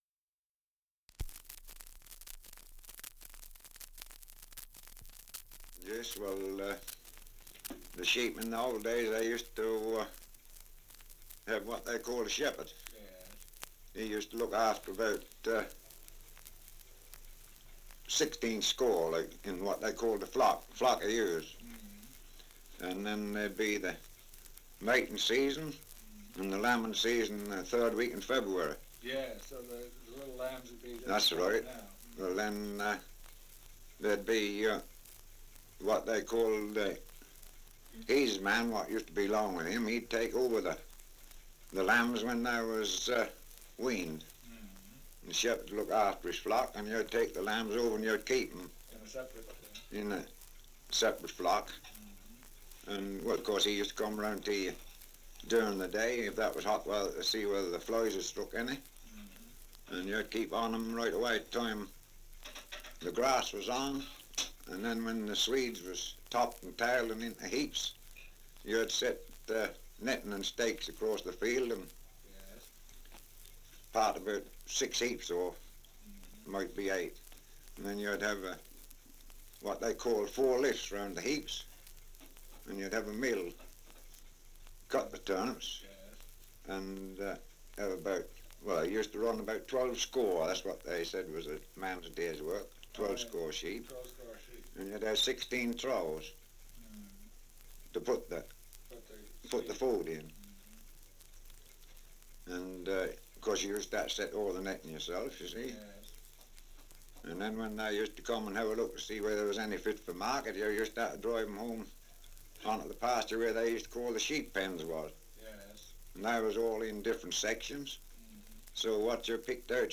2 - Survey of English Dialects recording in Docking, Norfolk
78 r.p.m., cellulose nitrate on aluminium